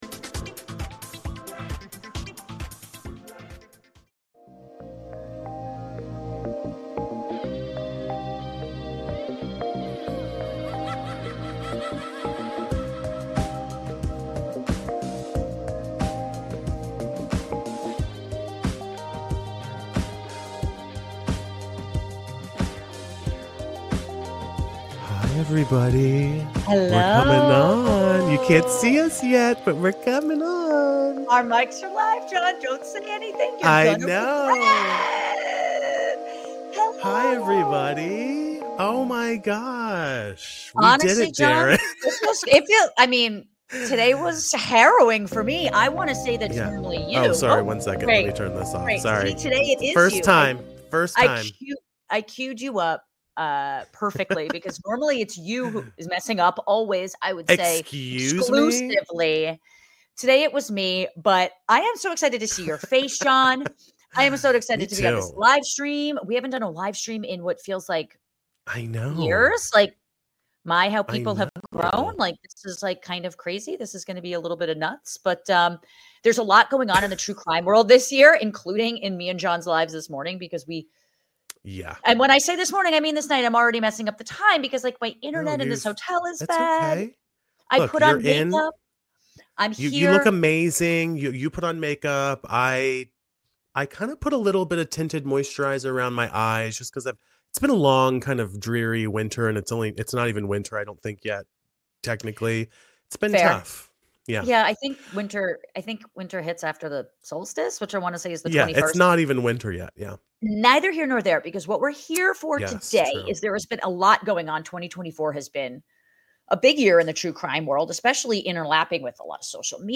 2024 True Crime Recap Live Stream